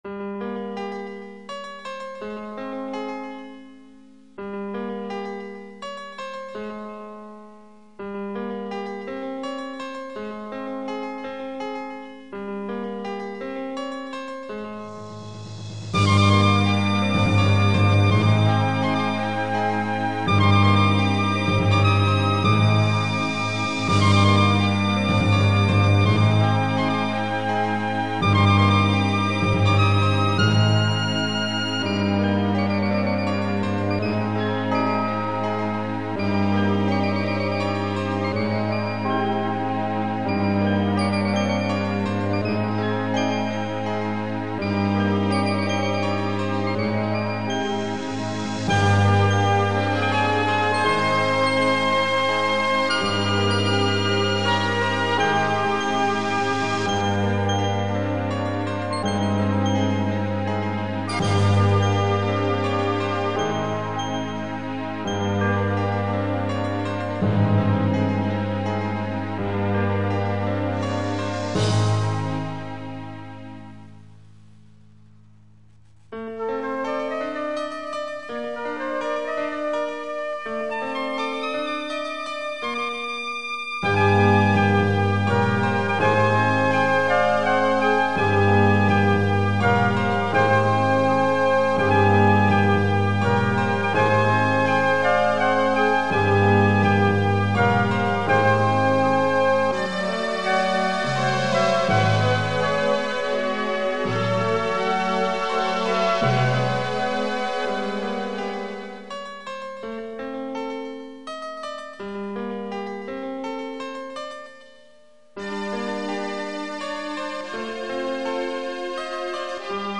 Elektronick� po��ta�ov� hudba m� v�dycky fascinovala u� od prvn�ch chv�l�, co jsem je sly�el.
Do�al jsem se a� s p��chodem programu OctaMED SoundStudio, kter� byl p�ehledn�m trackerem umo��uj�c�m multikan�lovou editaci 16-bit zvuku.